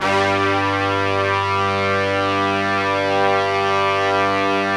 G2 POP BRASS.wav